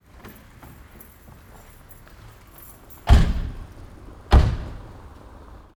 Car Door Close And Keys Sound
transport
Car Door Close And Keys